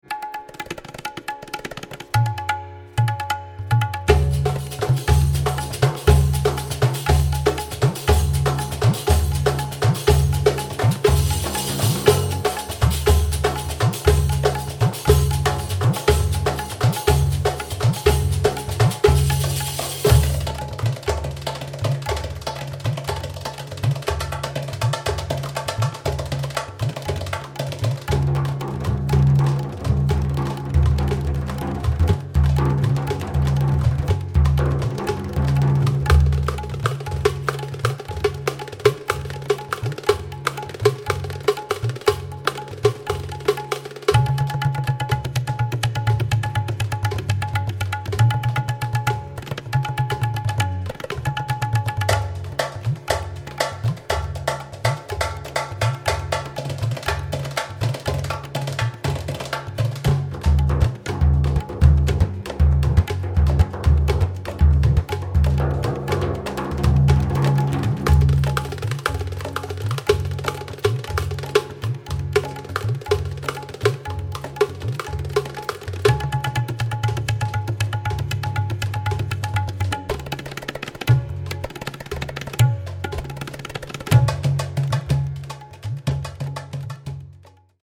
tabla
riq
frame drum
doumbek